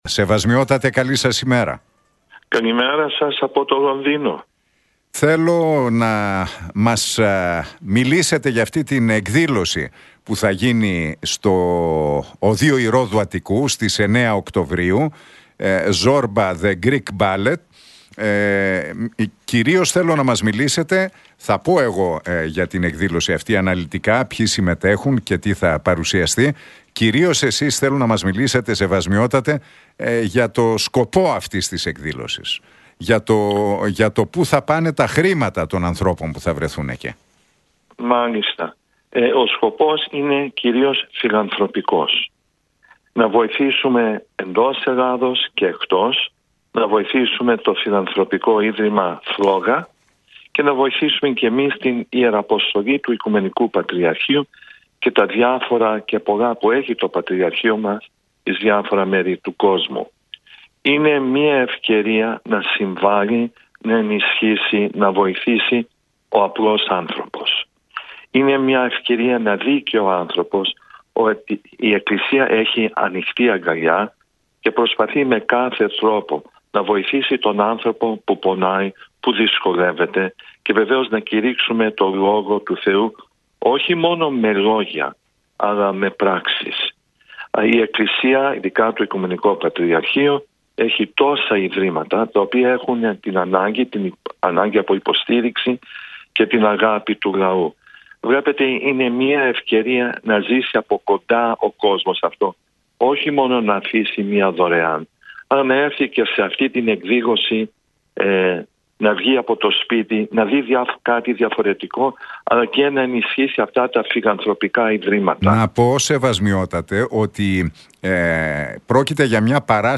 Για την εκδήλωση «Zorba The Greek Ballet» που θα πραγματοποιηθεί την Τετάρτη 9 Οκτωβρίου στο Ωδείο Ηρώδου Αττικού μίλησε ο Αρχιεπίσκοπος Θυατείρων και Μεγάλης Βρετανίας, κ.κ. Νικήτας στον Realfm 97,8 και τον Νίκο Χατζηνικολάου.